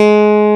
CLAV C3+.wav